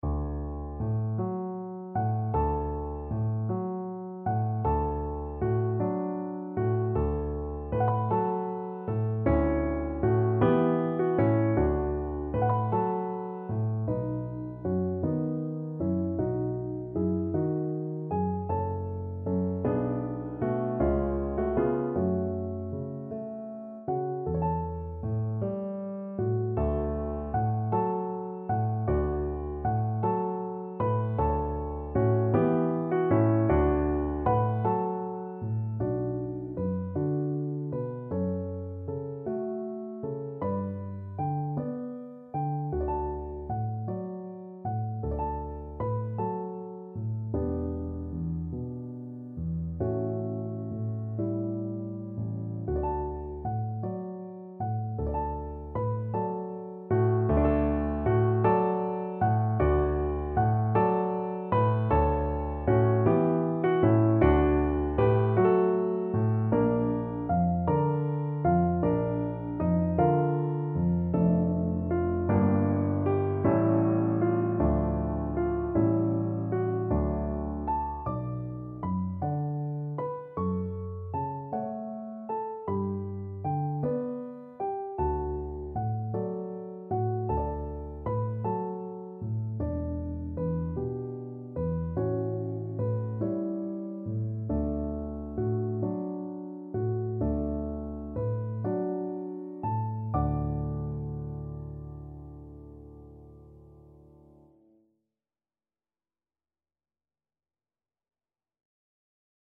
6/8 (View more 6/8 Music)
~. = 52 Allegretto